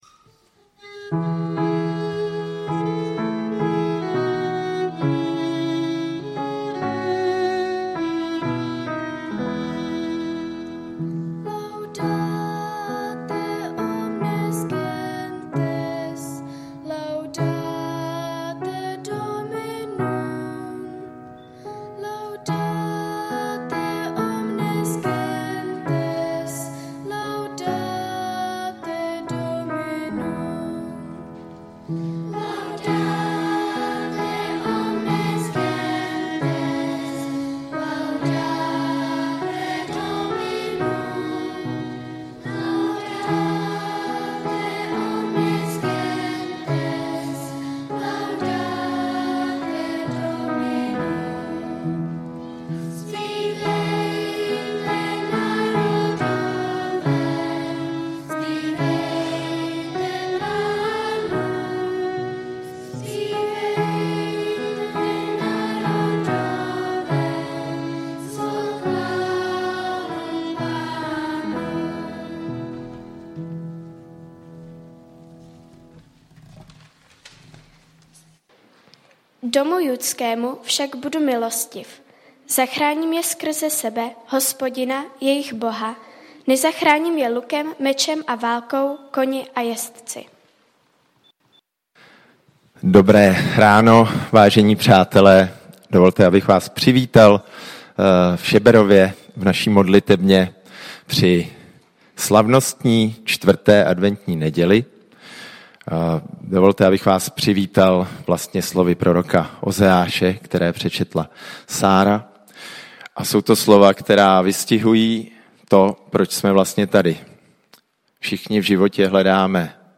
Nedělní bohoslužba: Vánoční slavnost